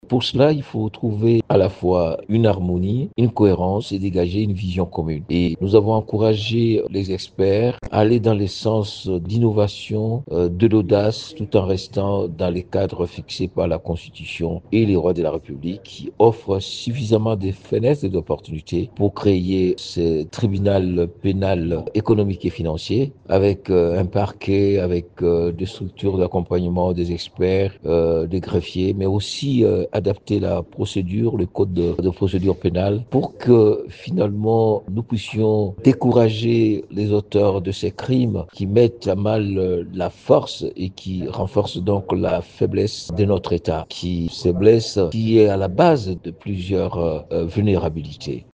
Jacques Djoli revient sur des options levées pour la création d’un tribunal spécial dans cet extrait :